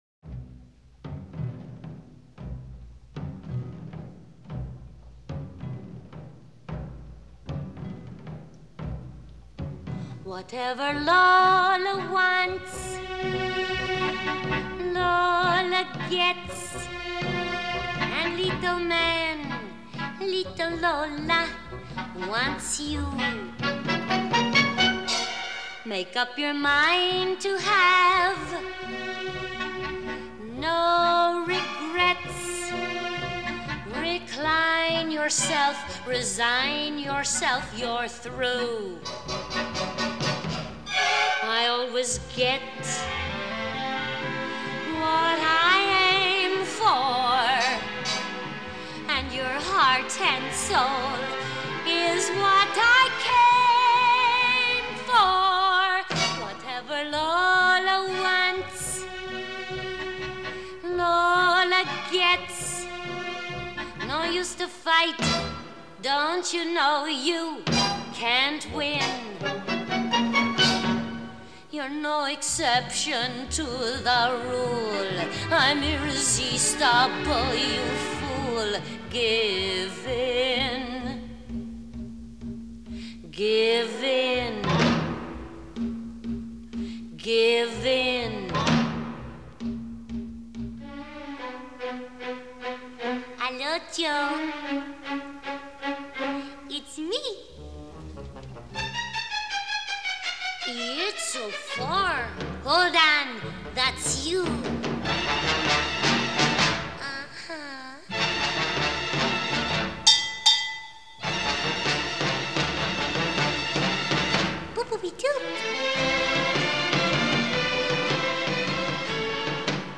1955   Genre: Musical   Artist